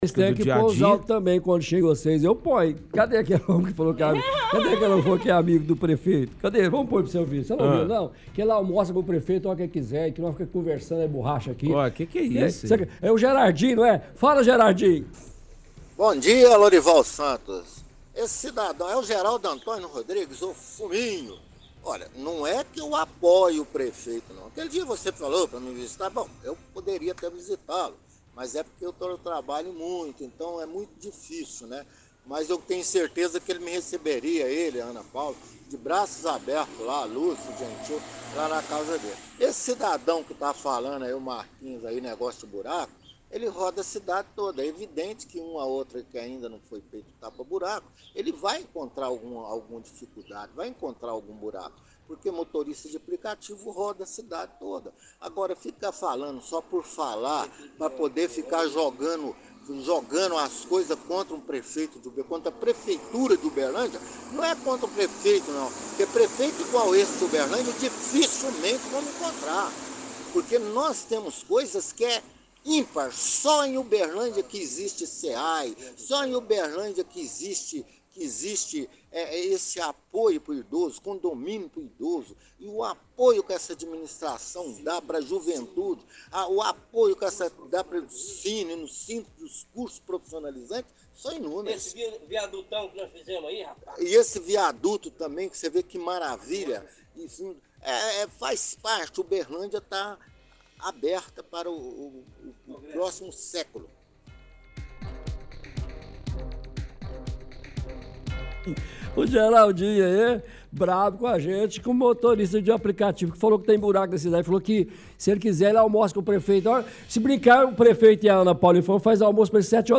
– Retransmissão de áudio de ouvinte defendendo o prefeito.